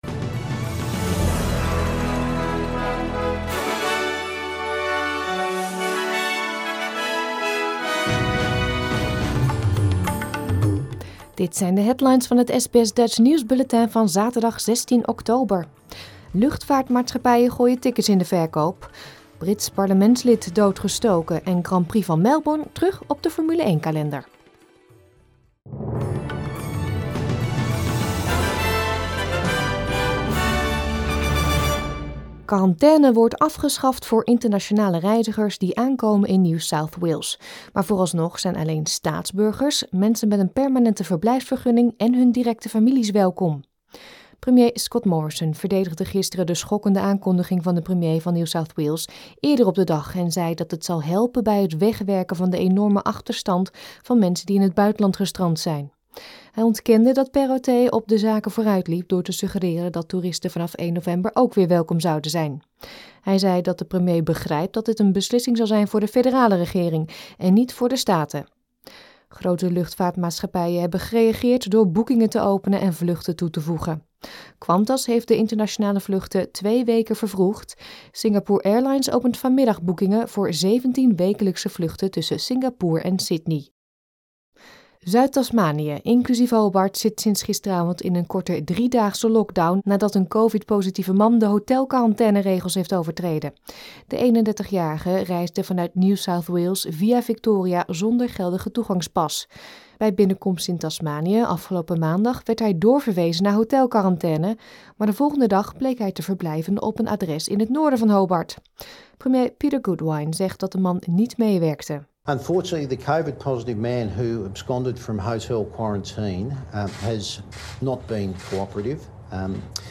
Nederlands/Australisch SBS Dutch nieuwsbulletin van zaterdag 16 oktober 2021